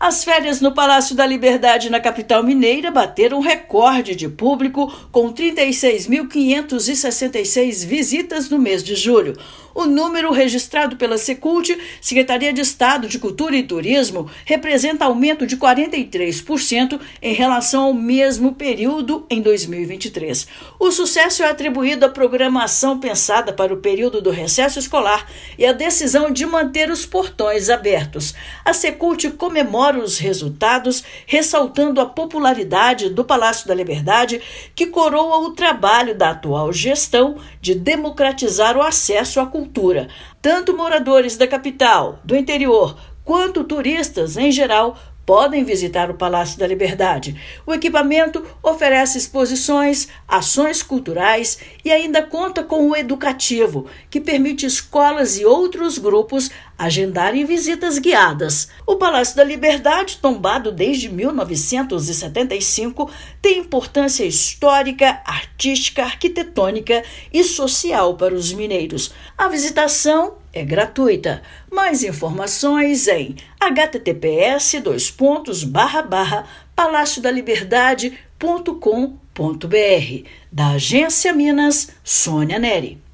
Apenas em julho, foram mais de 36 mil visitantes, crescimento de 43% em relação ao mesmo período de 2023; desde janeiro, mais de 164 mil visitantes estiveram no prédio histórico. Ouça matéria de rádio.